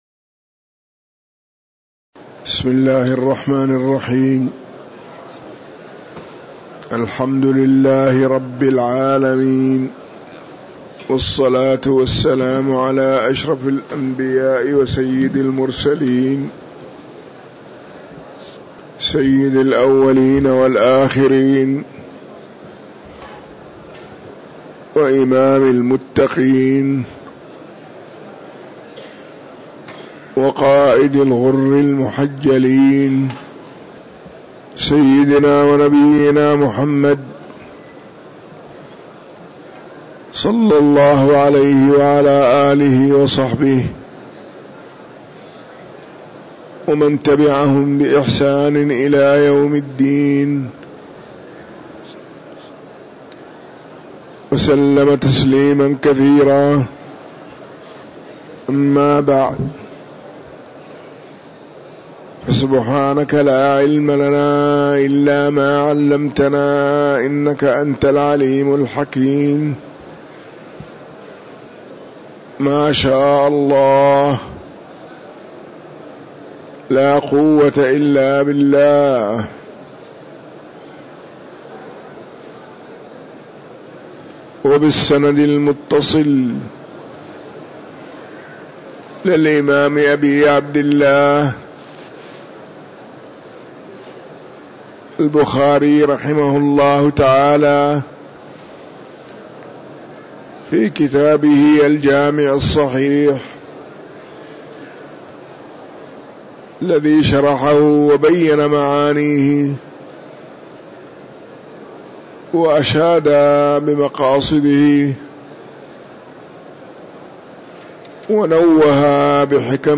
تاريخ النشر ٩ شعبان ١٤٤٤ هـ المكان: المسجد النبوي الشيخ